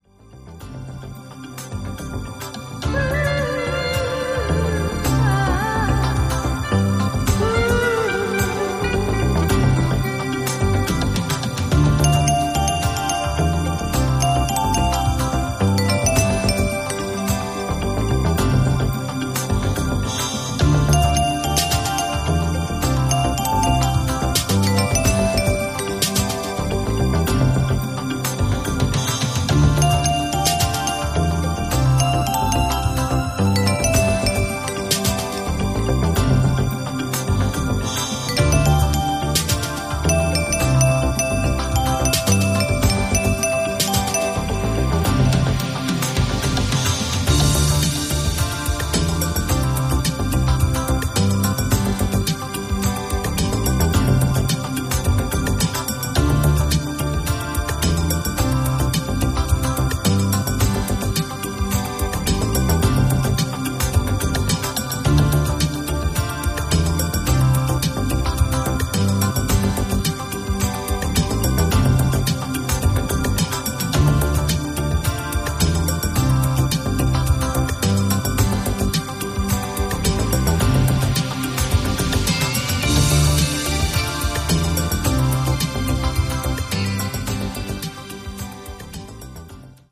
Balearic
Proggy guitars speak to the soul
woody xylophones and wispy leads ride on dubby bass